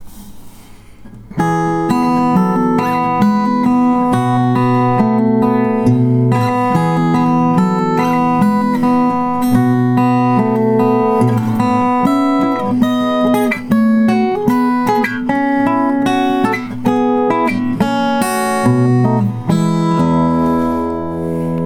These recordings (at least the ones where I am playing) are amateur recordings.
I use only the built-in microphones on the TASCAM recorder.
These are direct to digital recordings and therefore have the bright all-digital sound.
(These are just meant to try to demonstrate the sound of the guitar!)
B00 in Sitka spruce and Pacific madrone (cutaway)